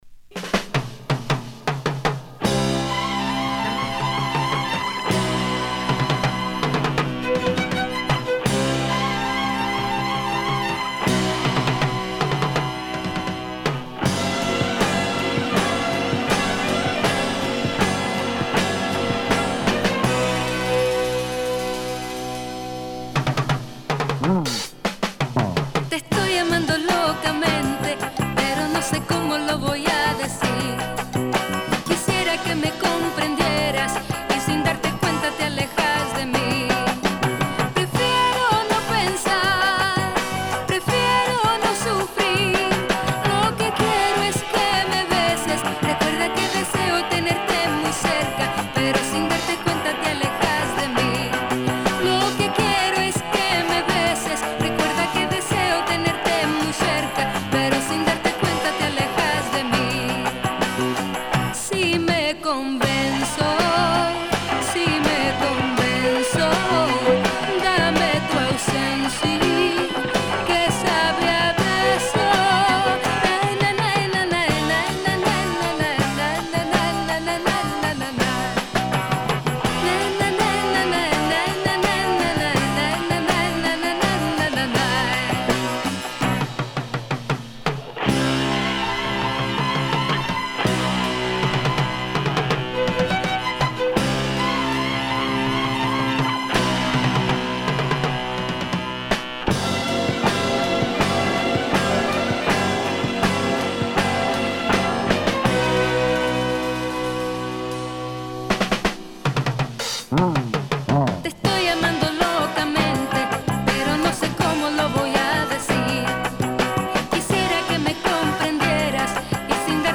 Published November 15, 2009 Garage/Rock Comments